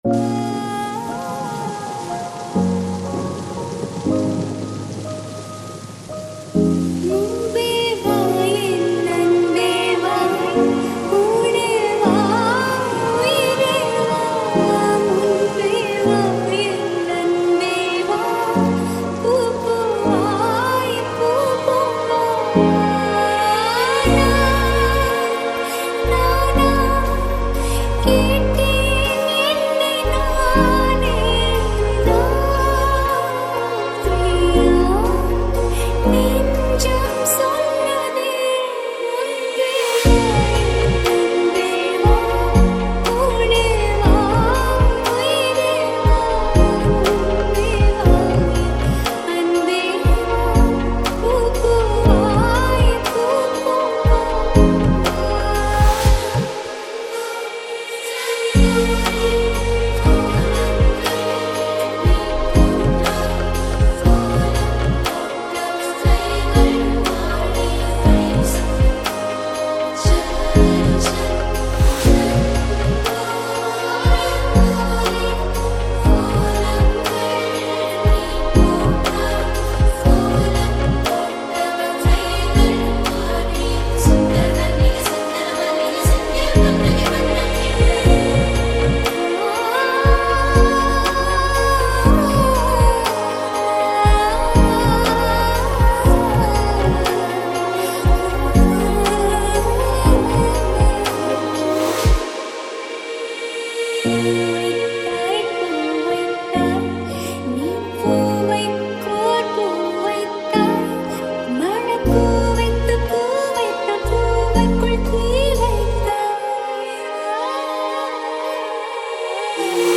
All TAMIL LOFI REMIX